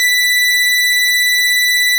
snes_synth_083.wav